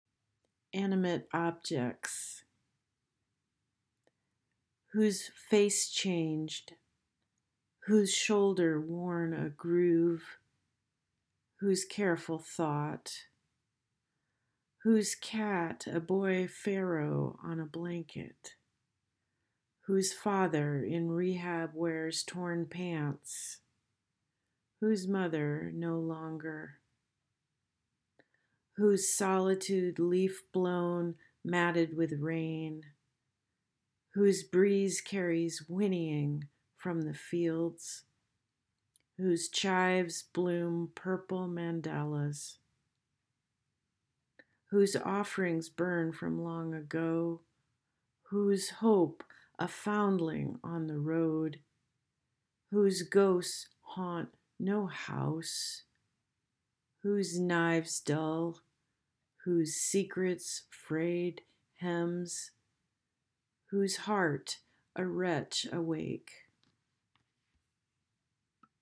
Press ‘Play’ to hear the author read their piece.